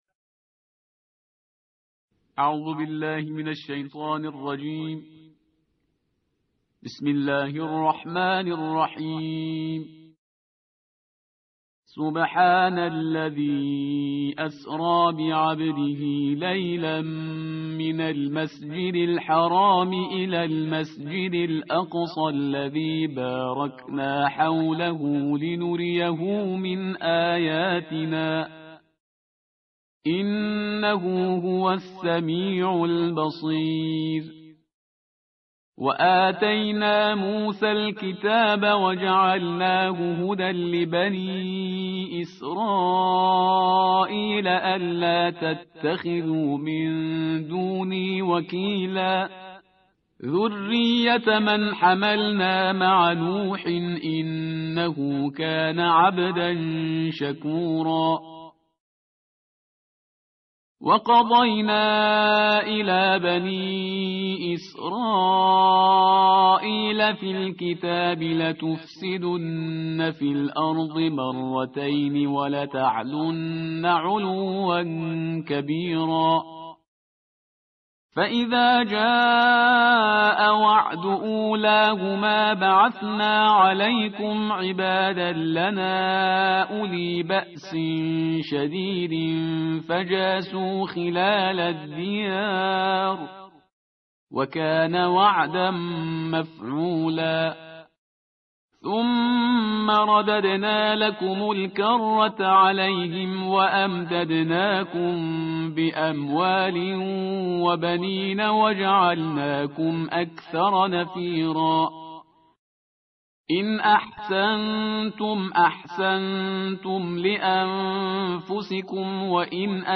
تحدیر و ترتیل جزء پانزدهم قرآن کریم + دانلود mp3